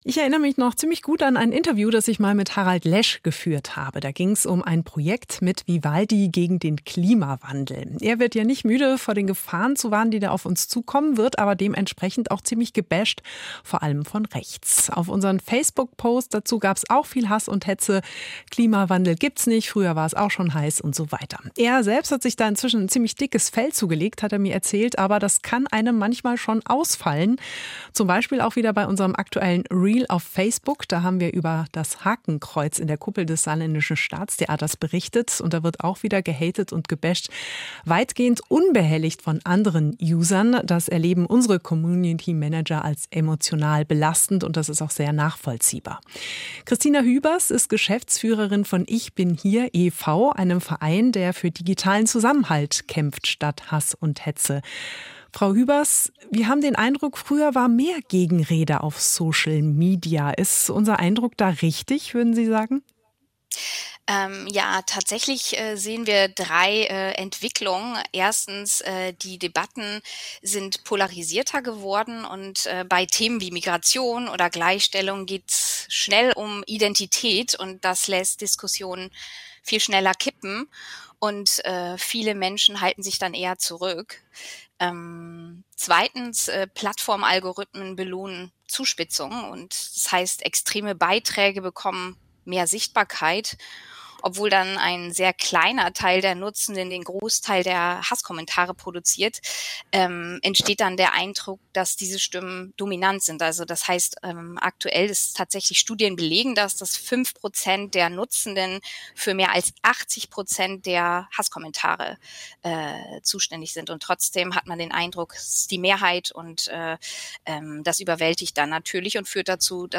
Hass im Netz begegnen: ichbinhier im Gespräch mit SR Kultur und Deutschlandfunk Nova